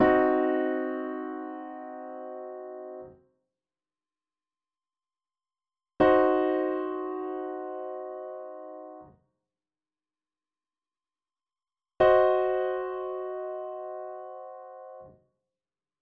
In second inversion, the tritone is on the bottom with an added minor third:
4. Diminished triads – Root, first and second inversions